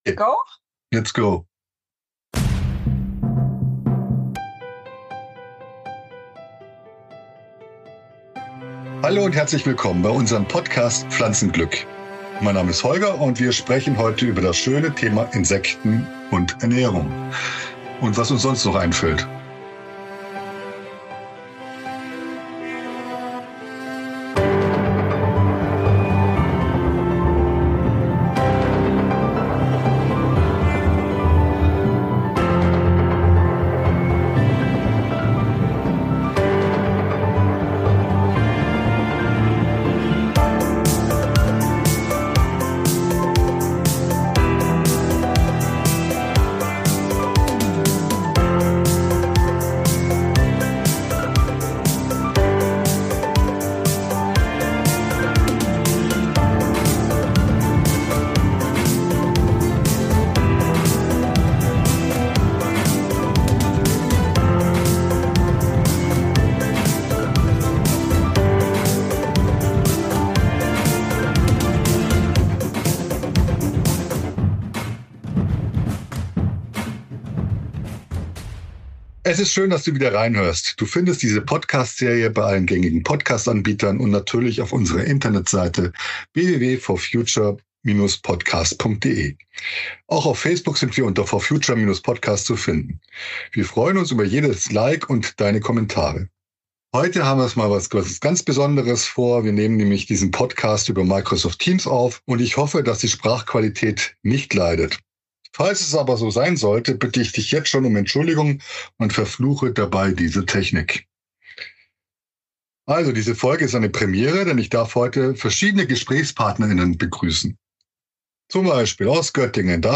Darüber diskutieren wir in unserer Runde. Unser heutiges Thema ist die umstrittene Erlaubnis Insekten als Nahrungsmittel in der EU zuzulassen.